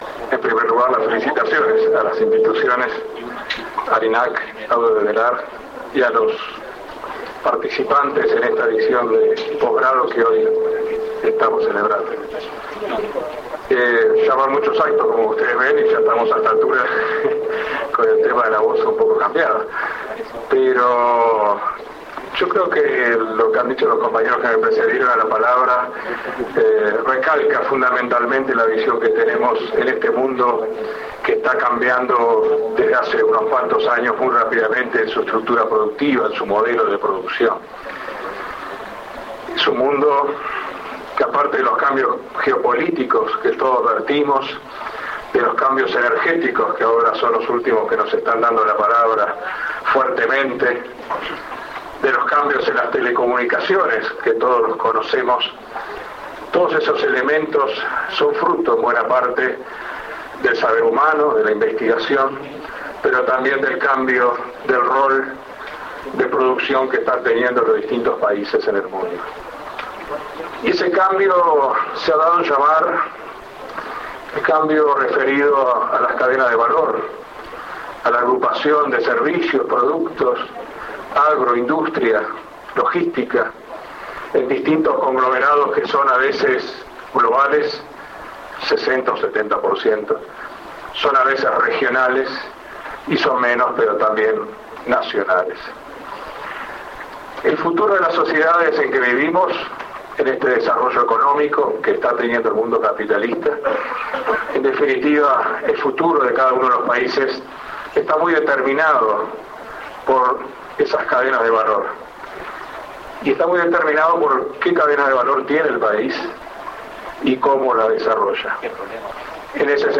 El Ministro de Industria, Energía y Minería Roberto Kreimerman felicitó a ambas instituciones por converger en el conocimiento, porque el desarrollo y el crecimiento son en base a la capacitación y a las ventajas naturales que tiene el país.